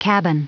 Prononciation du mot cabin en anglais (fichier audio)
Prononciation du mot : cabin